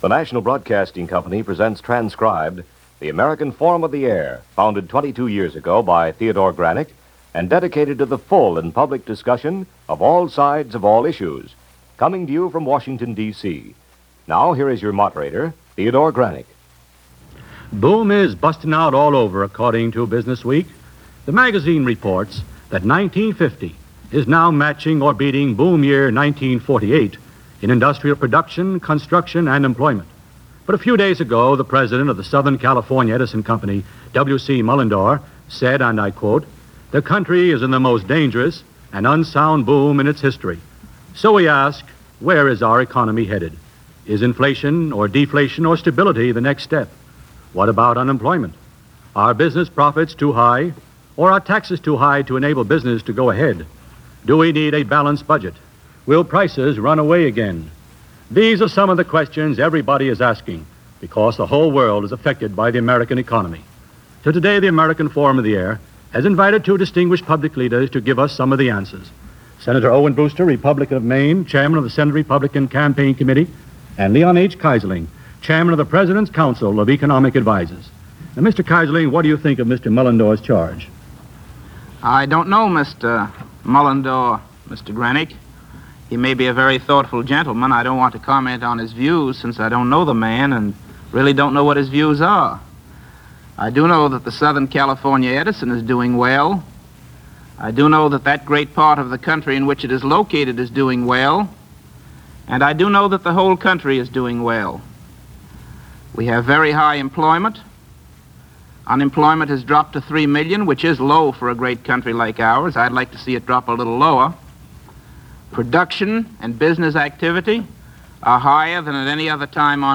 Discussion/debate with Sen. Owen Brewster and Leon H. Keyserling discussing the economy of 1950.